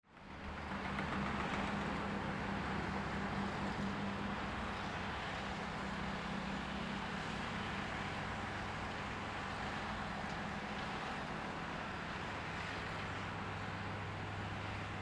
Traffic 11
Background Sound Effects, Transportation Sound Effects
traffic_11-1-sample.mp3